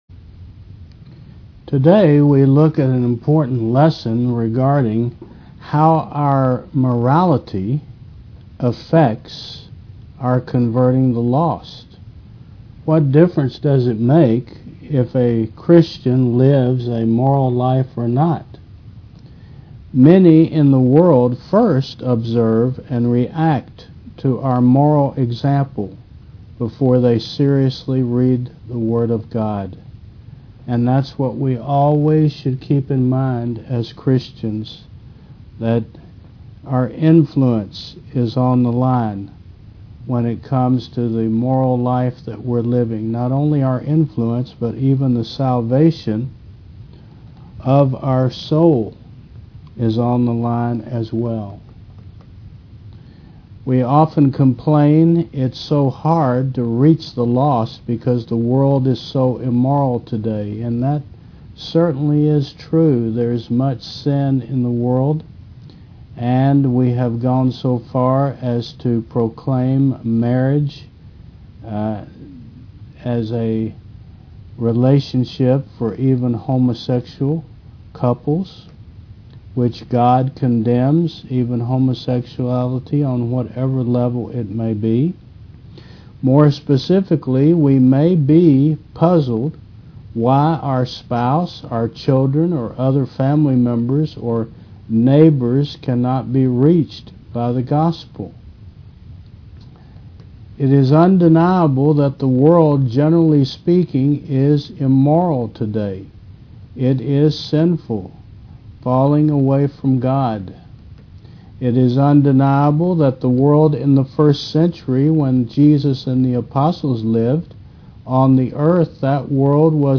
Service Type: Mon. 9 AM